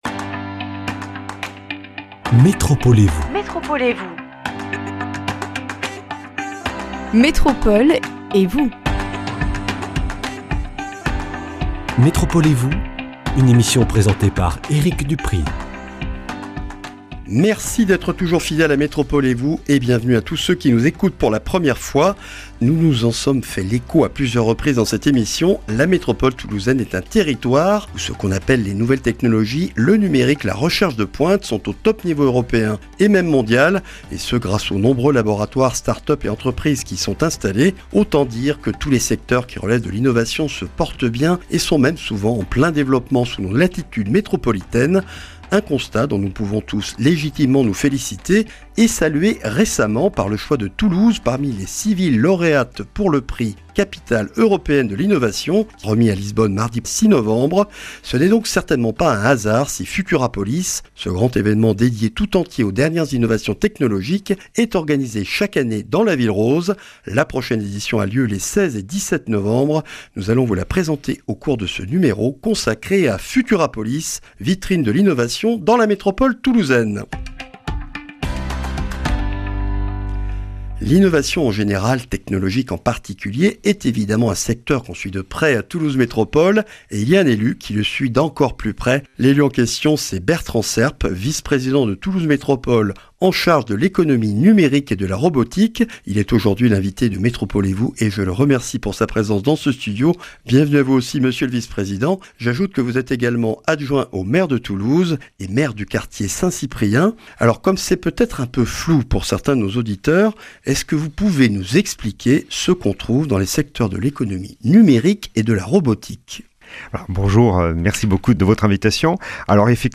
Rediffusion de l’émission consacrée au forum Futurapolis 2018 à Toulouse avec Bertrand Serp, Vice-président de Toulouse Métropole en charge de l’Économie numérique et de la Robotique, adjoint au maire de Toulouse pour l’Innovation et le Numérique..